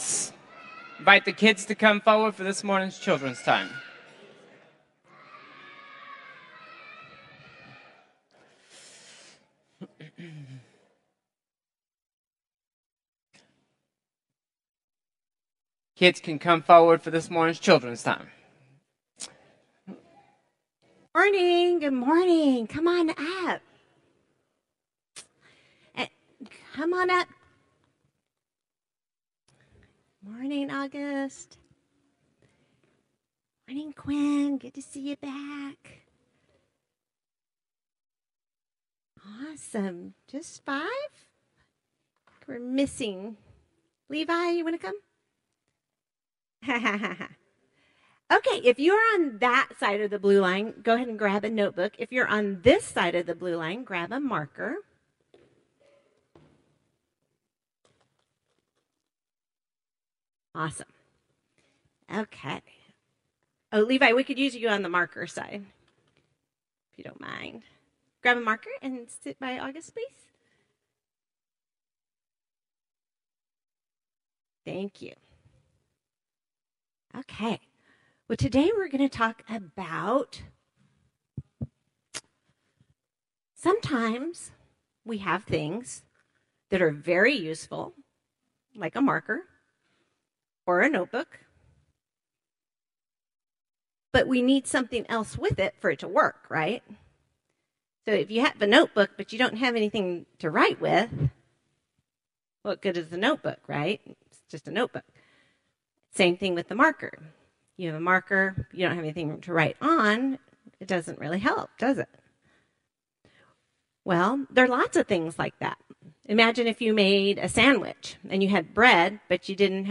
Children’s Time